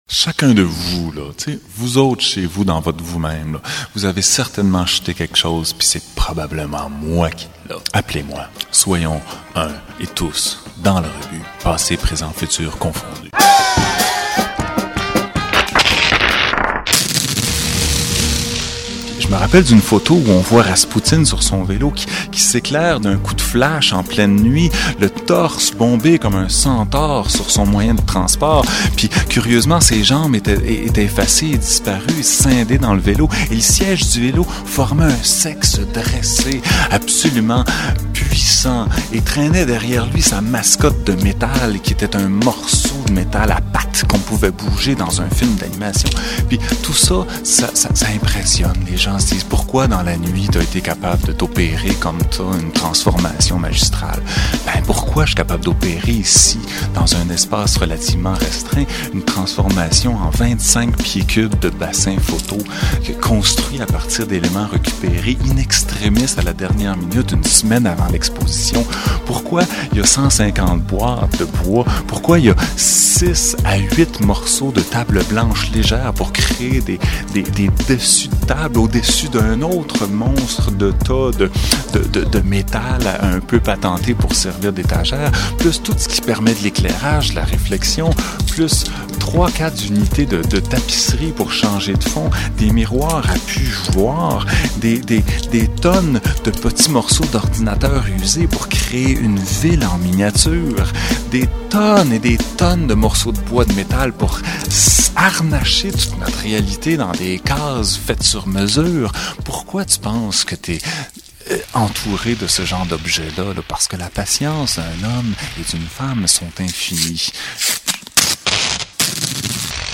Reportage 9.59'